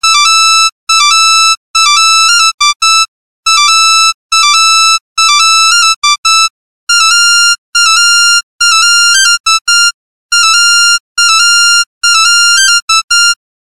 NokiaSamsung рингтоны. Арабские
(народная)